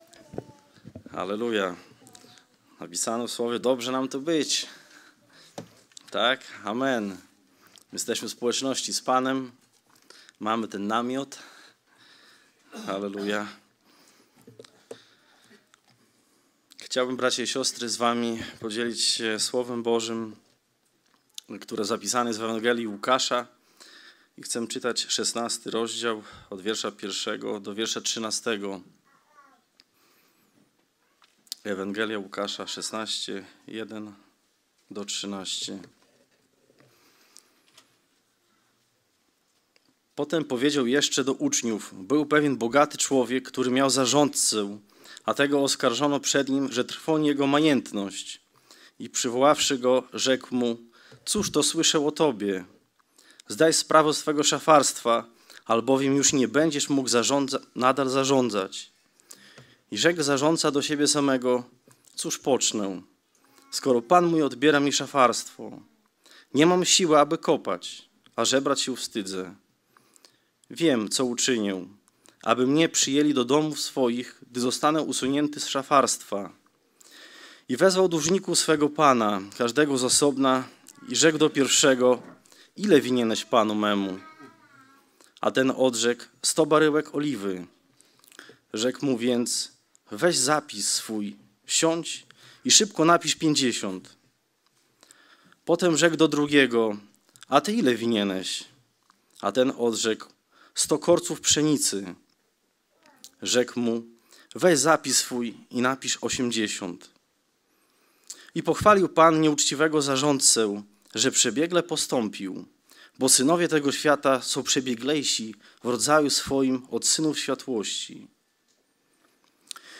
Skorzystaj z przycisku poniżej, aby pobrać kazanie na swoje urządzenie i móc słuchać Słowa Bożego bez połączenia z internetem.